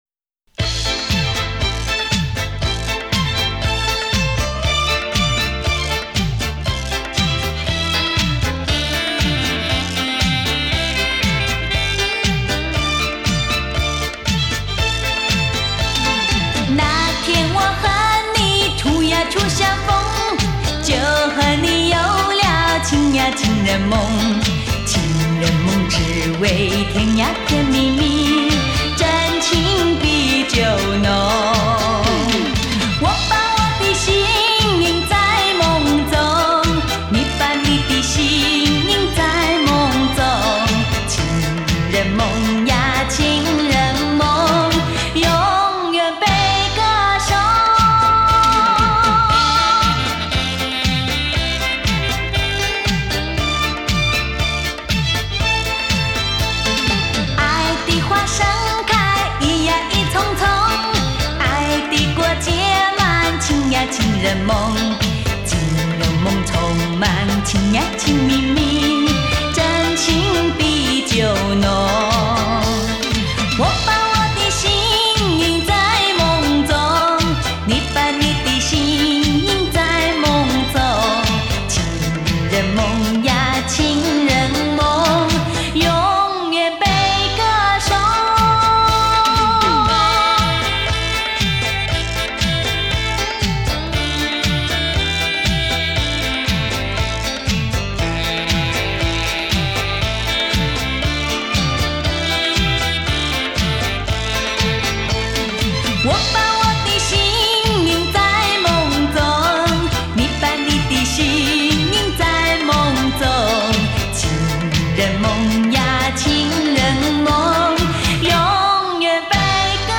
她的嗓音独特，天生带点淡淡的忧愁